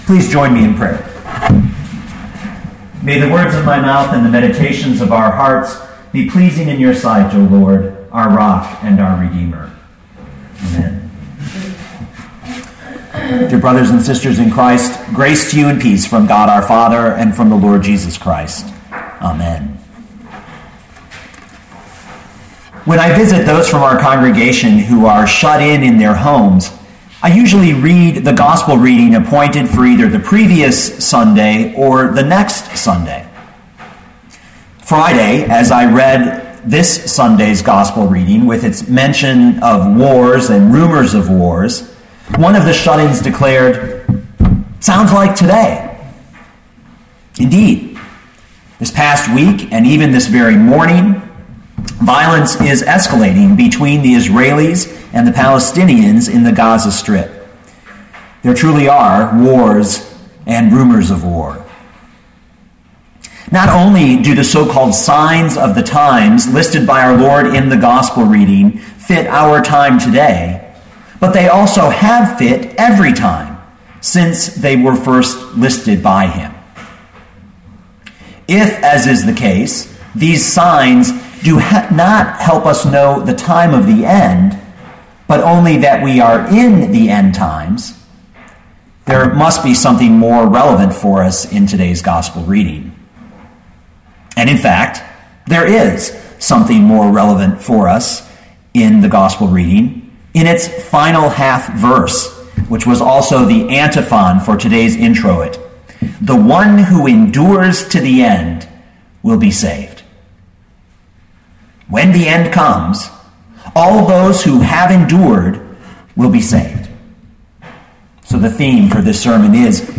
2012 Mark 13:1-13 Listen to the sermon with the player below, or, download the audio.